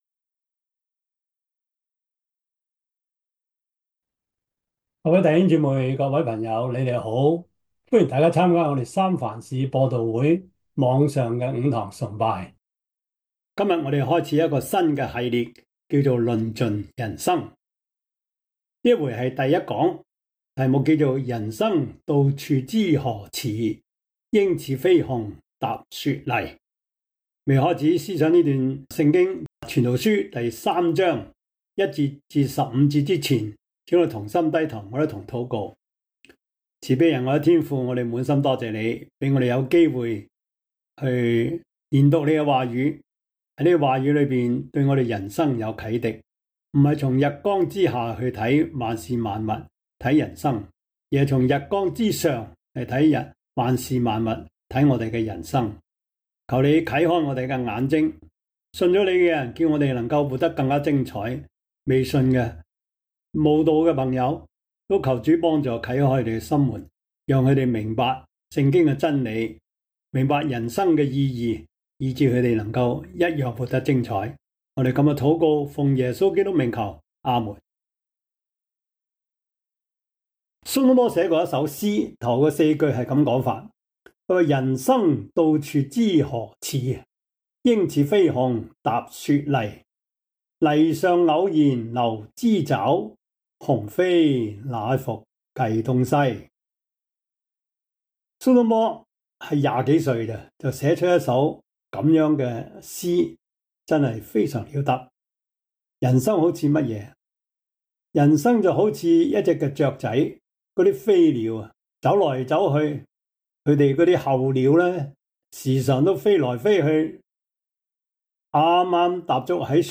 傳道書 3:1-15 Service Type: 主日崇拜 傳道書 3:1-15 Chinese Union Version